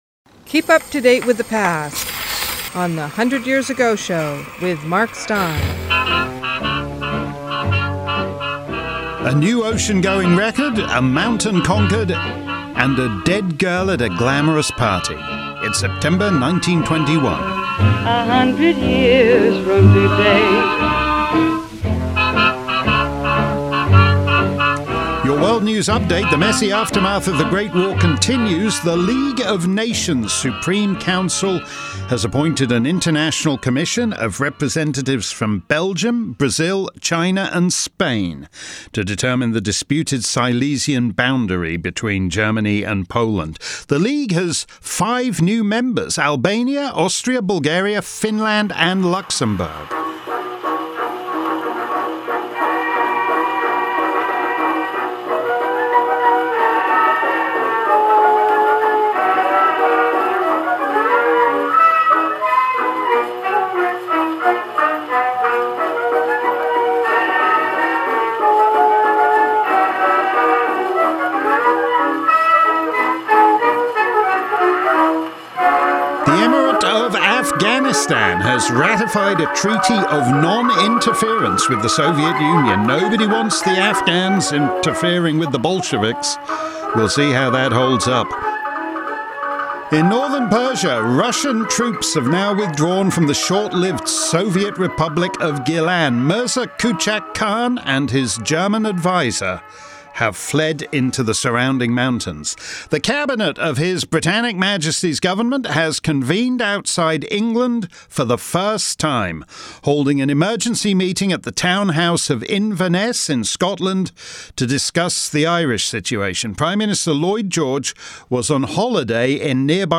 It's like sitting in the library reading microfilm of old newspapers with music from the era playing in the background, but better because you don't have to load the microfilm and shuffle through it, Mark selects the most interesting bits and reads them with great flair.